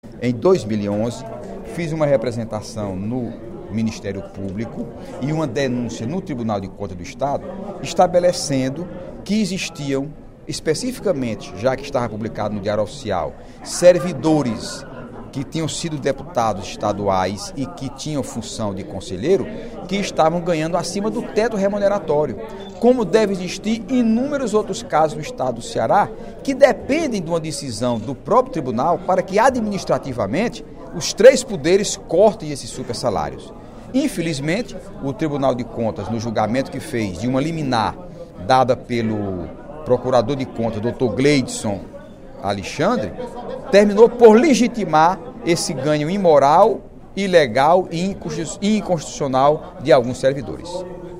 O deputado Heitor Férrer (PDT) criticou, durante o primeiro expediente da sessão plenária desta quarta-feira (16/10), a decisão do Tribunal de Contas do Estado (TCE), que, no dia 21 de agosto, rejeitou o pedido de liminar do Ministério Público de Contas (MPC) que determinava o bloqueio do pagamento de salários acima do teto constitucional a servidores do Estado, nos poderes Executivo, Legislativo e Judiciário.